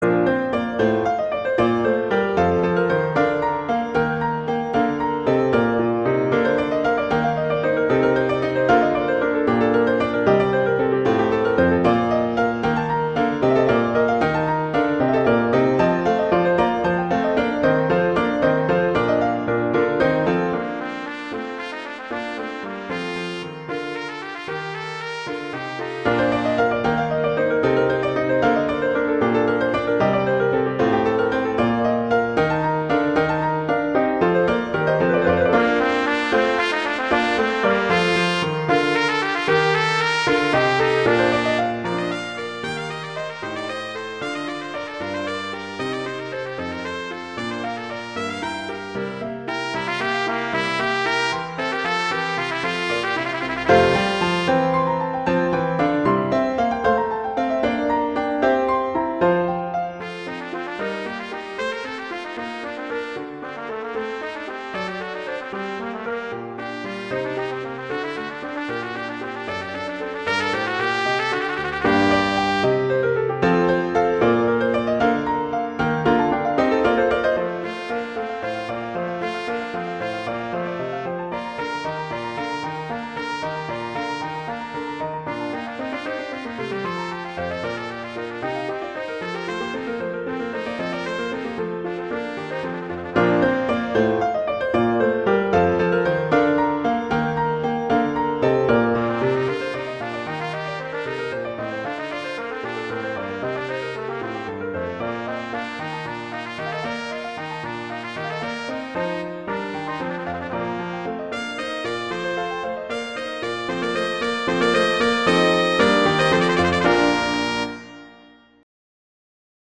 Instrumentation: trumpet & piano
transcription for trumpet and piano
classical, concert
Bb major
♩=60-114 BPM (real metronome 60-112 BPM)